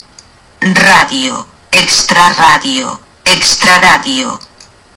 pero una doble r se usa para mantener la pronunciaci�n de la r inicial
extrarradio.mp3